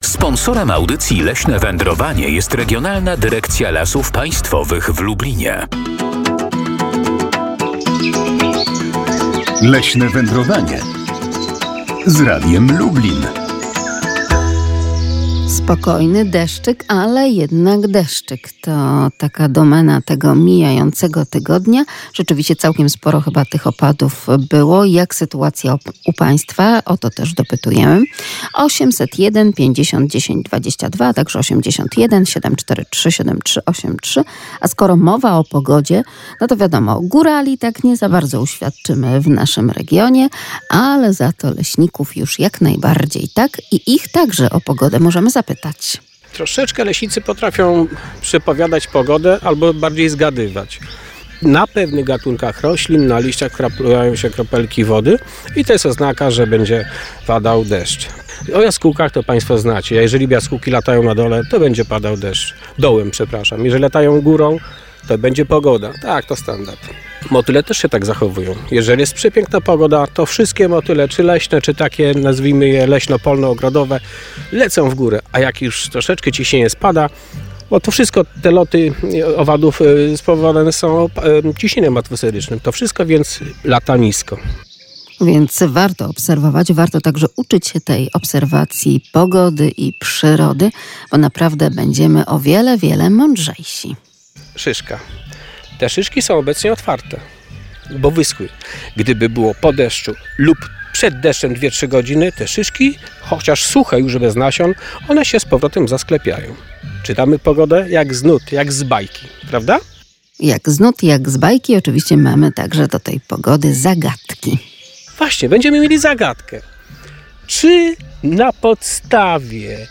W tej uroczystości będziemy także uczestniczyć z mikrofonem radiowym.
Nie zabraknie także relacji prosto z lasu na Podlasiu.